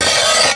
17. 17. Percussive FX 16 ZG